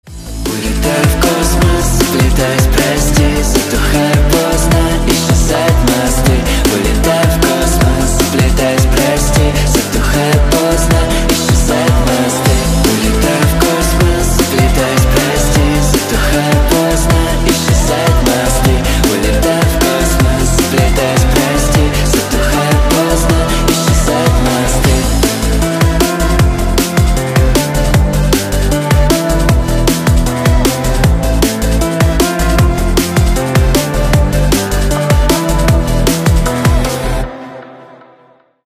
• Качество: 320, Stereo
мужской вокал
громкие
женский голос
dance
Electronic
электронная музыка